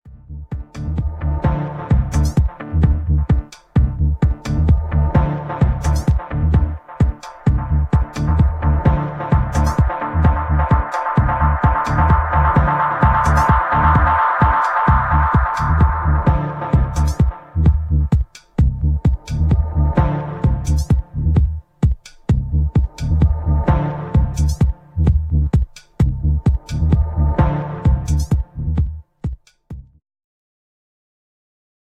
Also included is a deep dub mix of this track."
Second in steps to the minimal house rhythm, deep bass and
perfectly placed percussion but reworked well.